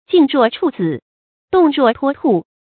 注音：ㄐㄧㄥˋ ㄖㄨㄛˋ ㄔㄨˇ ㄗㄧˇ ，ㄉㄨㄙˋ ㄖㄨㄛˋ ㄊㄨㄛ ㄊㄨˋ